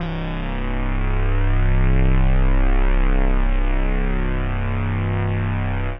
C1_trance_lead_2.wav